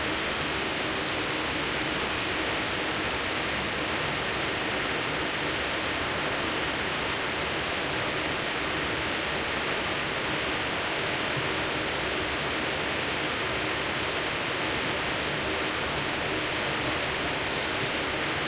showerLoop.ogg